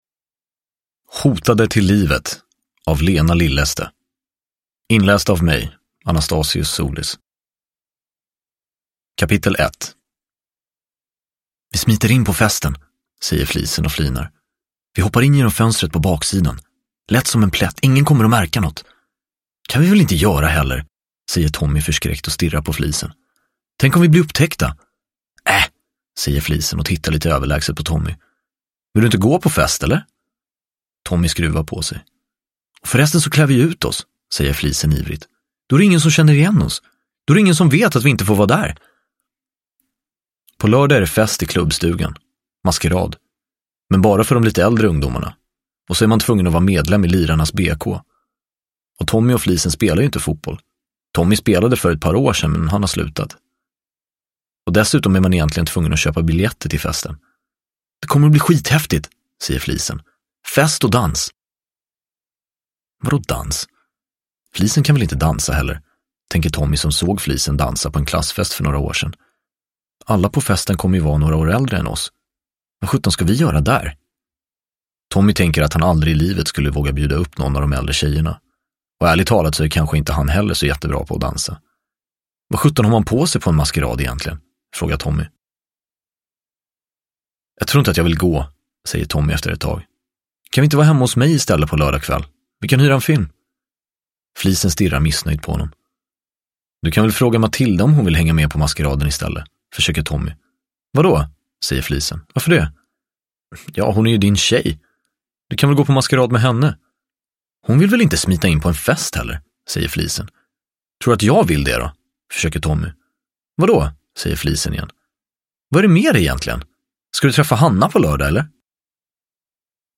Hotade till livet! – Ljudbok – Laddas ner
Uppläsare: Anastasios Soulis